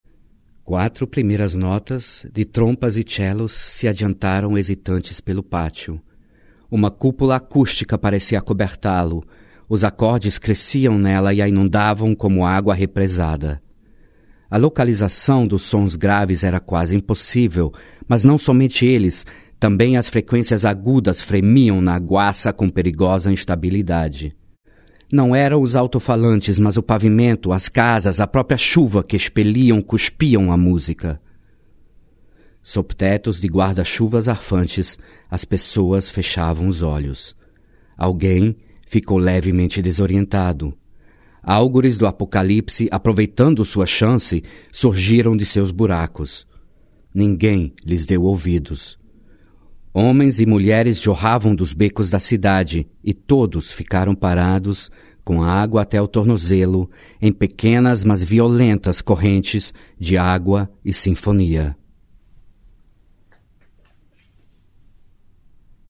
>>> der gesamte Abschnitt, eingelesen heute früh hier in Rio de Janeiro. Entschuldigen Sie die Hintergrundgeräusche. Rio ist eine laute Stadt.